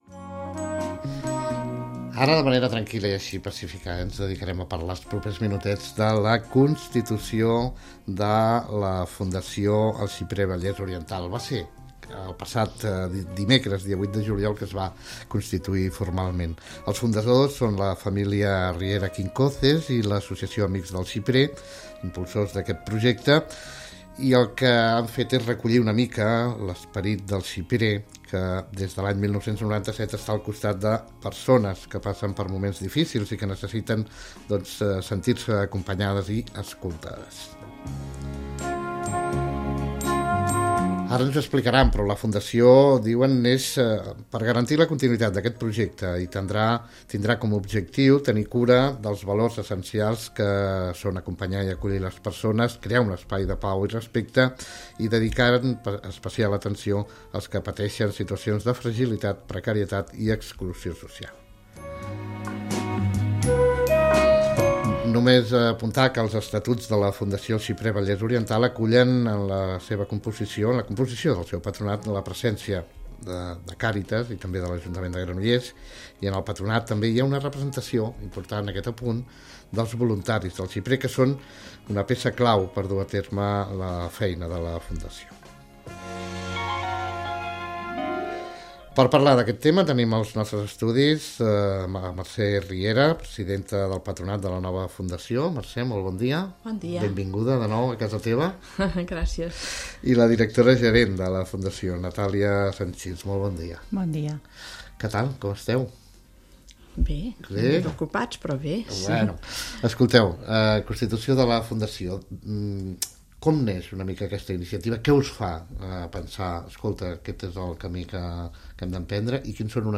EL passat dia 23 de juliol s'explica què és i per què ha nascut la Fundació El Xiprer. Escolteu entrevista